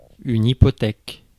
Ääntäminen
Ääntäminen France: IPA: /i.pɔ.tɛk/ Haettu sana löytyi näillä lähdekielillä: ranska Käännös Konteksti Ääninäyte Substantiivit 1. mortgage laki US 2. obstacle US 3. bond US Suku: f .